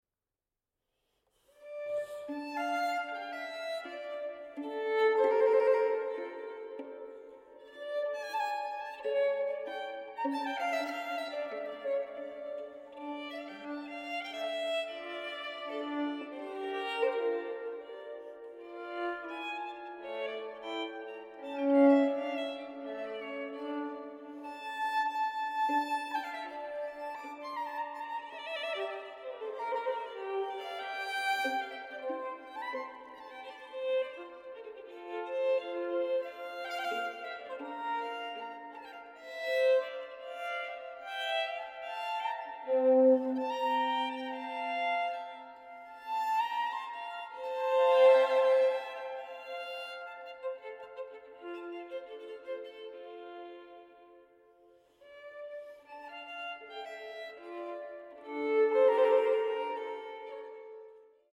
• Genres: Baroque, Classical, Strings